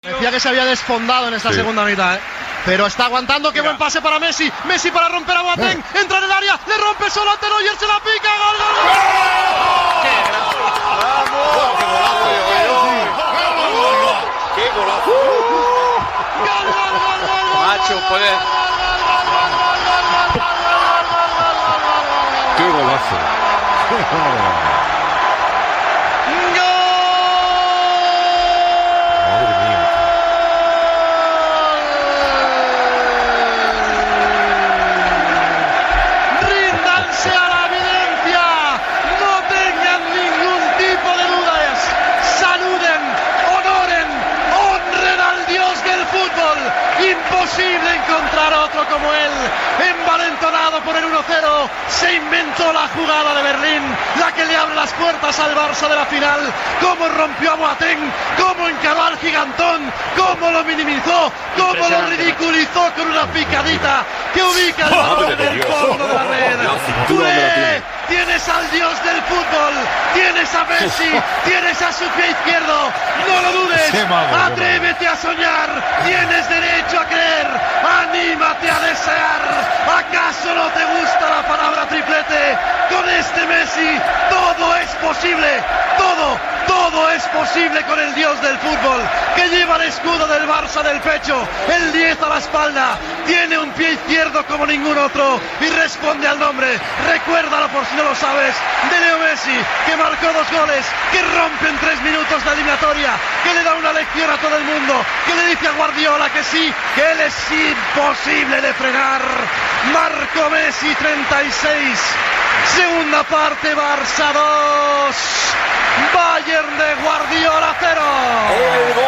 Narració, des del Camp Nou de Barcelona, del gol de Leo Messi al partit d'anada de la semifinal de la Copa d'Europa de Futbol masculí entre el Futbol Club Barcelona i el Bayern Munich (El partit va acabar 3 a 0)
Esportiu